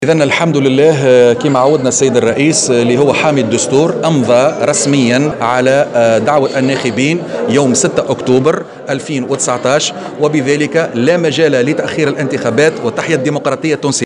أكد أنيس الجربوعي عضو الهيئة المستقلة للإنتخابات في تصريح لمراسلة الجوهرة "اف ام" امضاء رئيس الجمهورية رسميا على دعوة الناخبين يوم 6 أكتوبر القادم.